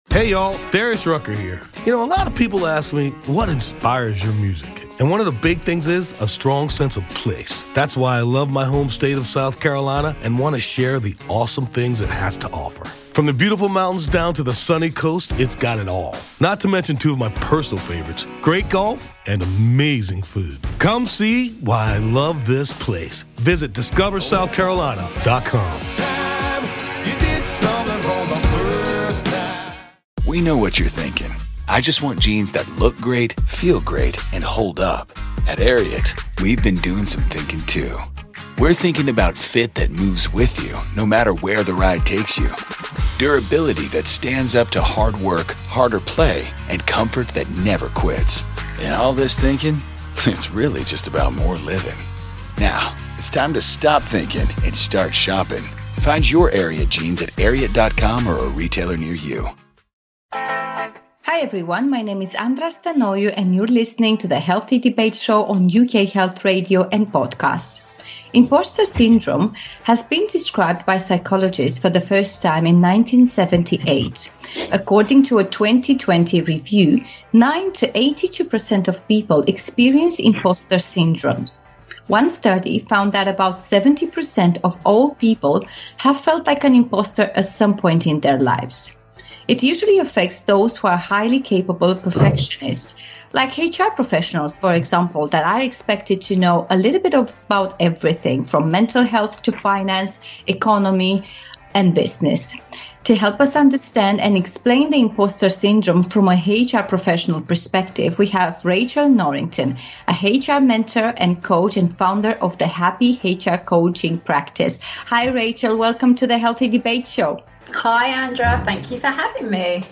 is joined by guests with various backgrounds, analysing mental and public health from every angle: medical, psychological, spiritual, or any other relevant direction.